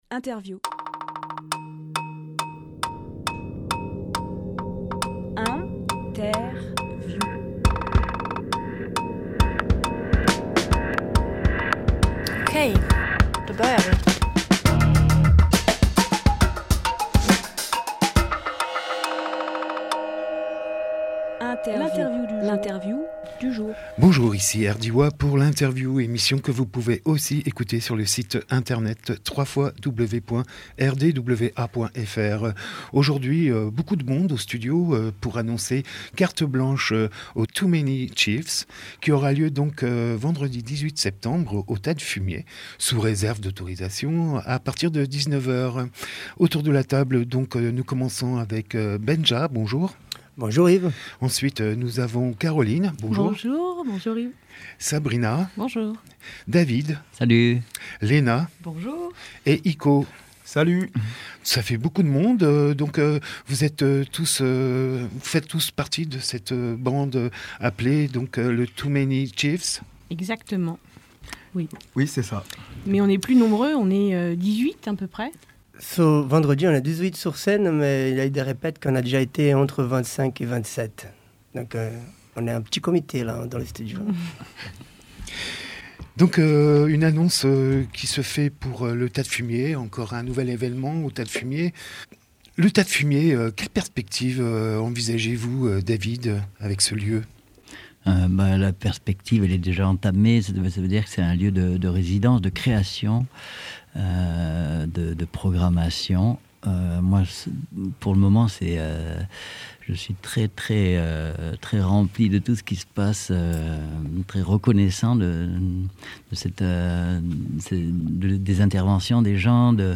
Emission - Interview Carte blanche à Too Many Chiefs Publié le 17 septembre 2020 Partager sur…
15.09.20 Lieu : Studio RDWA Durée